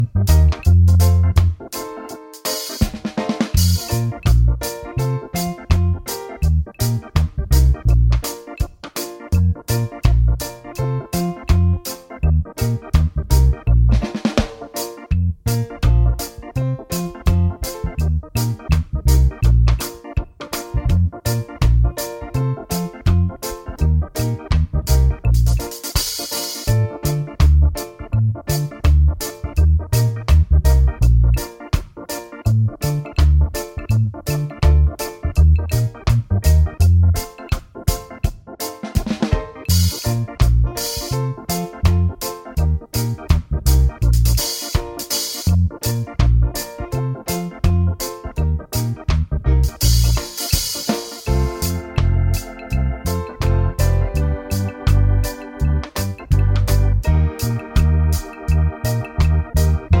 Minus Main Guitar For Guitarists 2:42 Buy £1.50